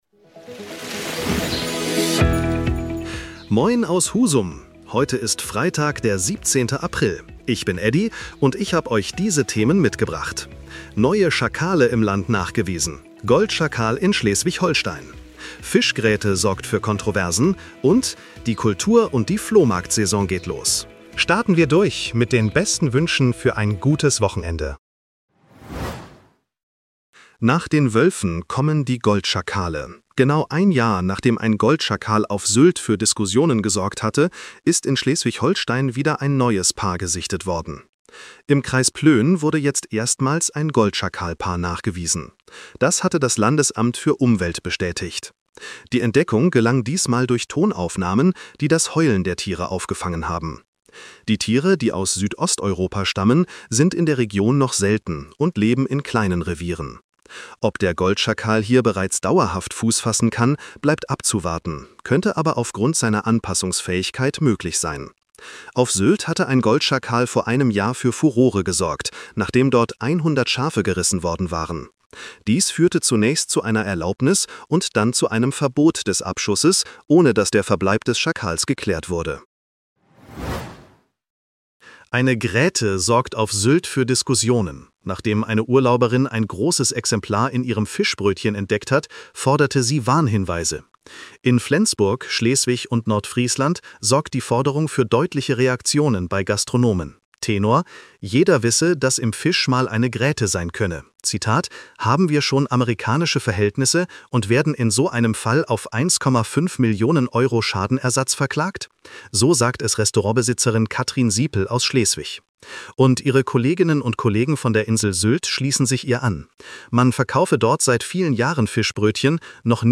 In unserem regionalen Nachrichten-Podcast